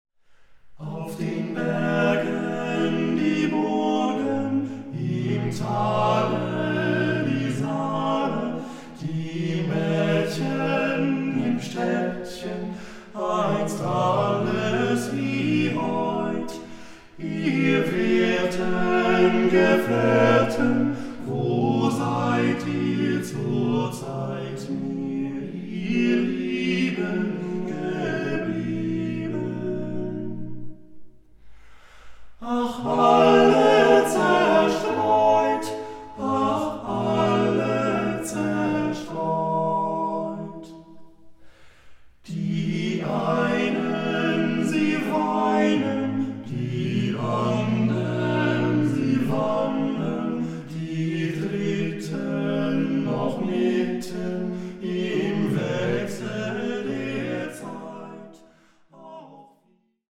the eight singers revive an entire folk culture!